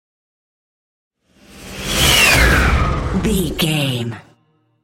Airy whoosh large
Sound Effects
futuristic
whoosh
sci fi